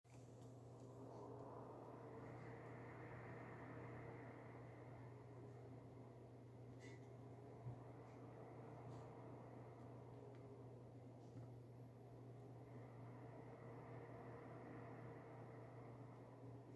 Die Folge, die Lüfter erzeugen ein auf- und abschwellendes Geräusch durch die stark schwankende Drehzahl.
(ist auch noch keine 2 Jahre alt, vorher verbaute Lüfter auf den gleichen Anschlüssen zeigten dieses Verhalten nicht) Anbei habe ich eine Hörprobe des Geräuschs angehängt, wenn man den Ton laut genug stellt hört man das auf- und abschwellende Lüftergeräusch deutlich.